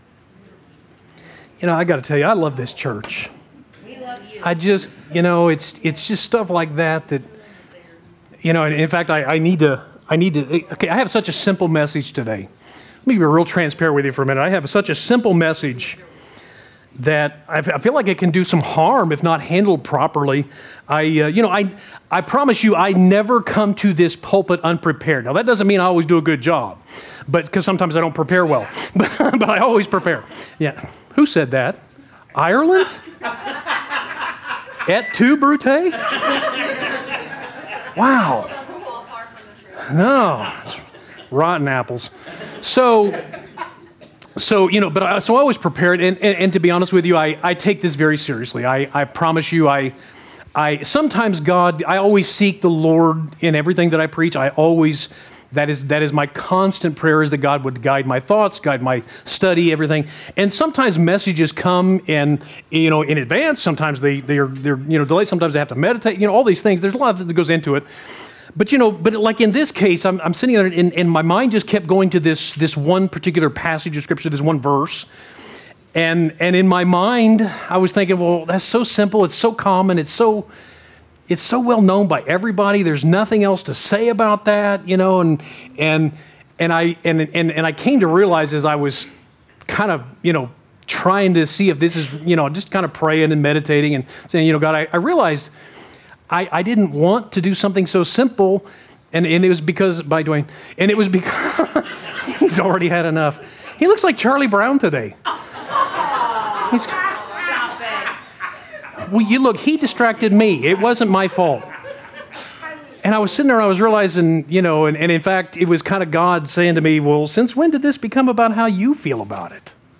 3-6-22 Sunday Message